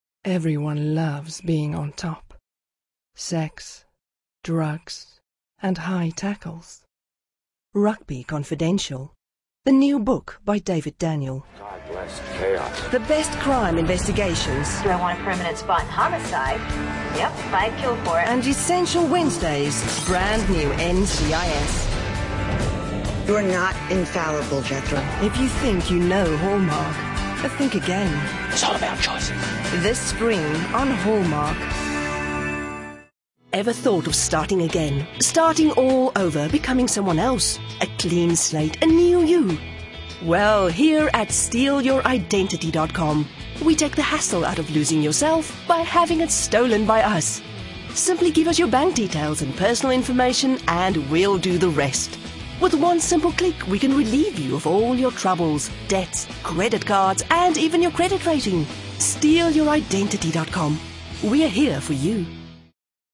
Sprechprobe: Werbung (Muttersprache):
Afrikaans, South African English, warm, reassuring, authoritive